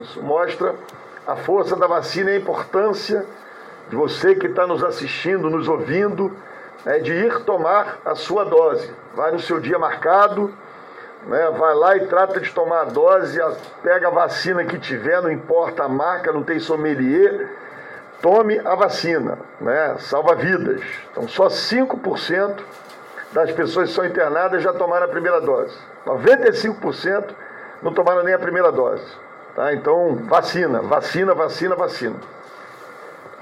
O prefeito Eduardo Paes, disse durante a divulgação do Boletim epidemiológico desta sexta-feira, no Centro de Operações Rio, que houve um pequeno aumento de casos de Covid-19 na última semana.